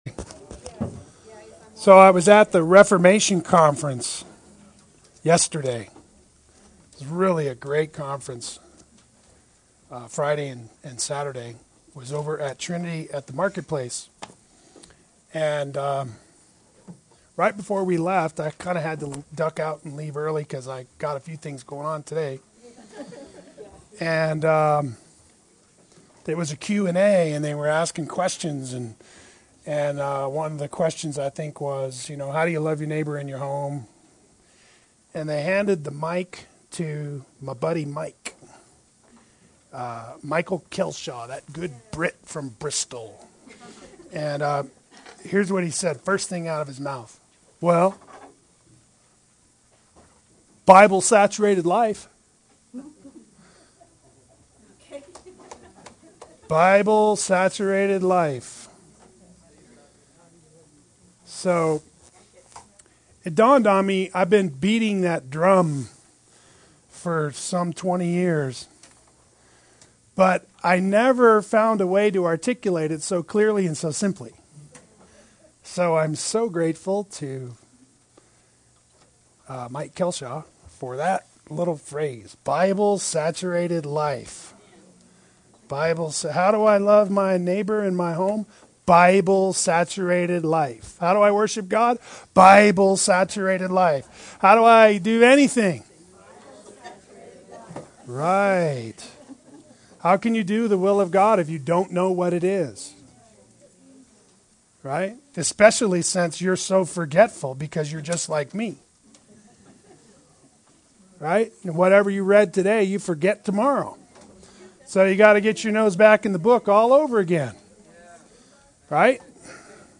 Play Sermon Get HCF Teaching Automatically.
Part 3 Adult Sunday School